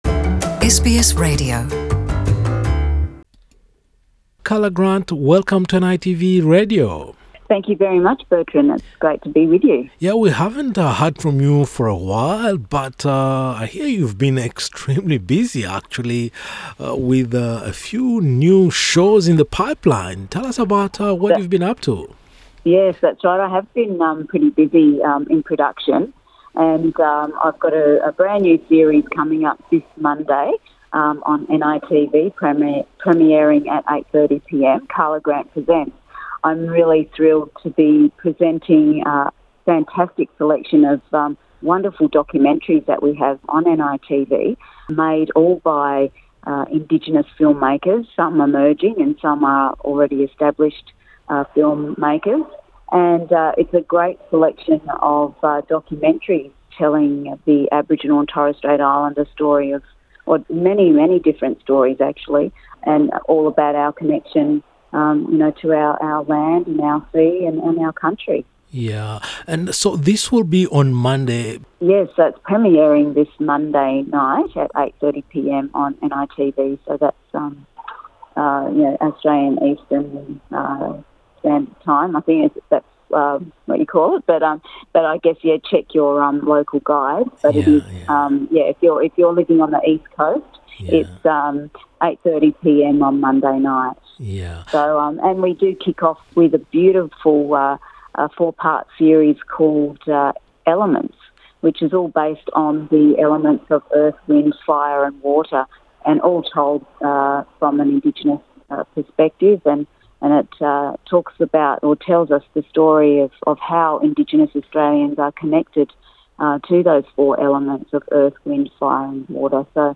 Conversation with Karla Grant about her upcoming new TV programs on NITV.